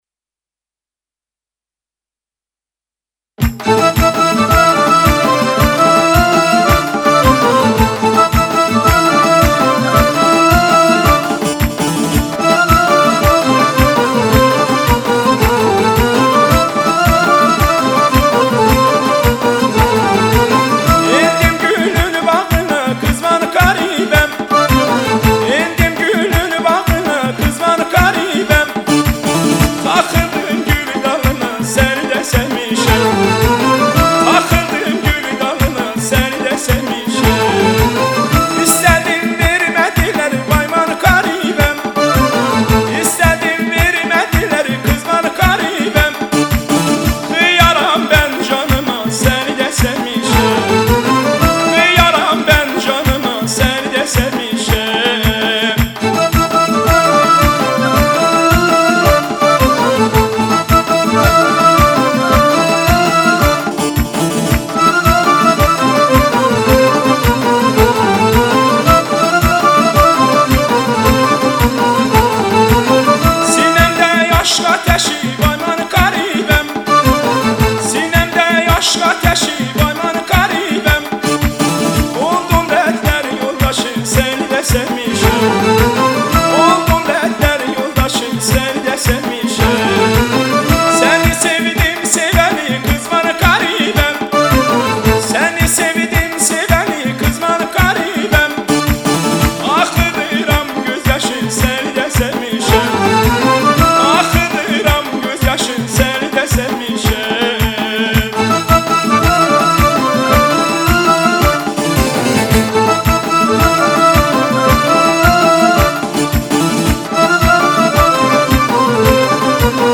دانلود آهنگ ترکی